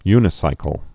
(ynĭ-sīkəl)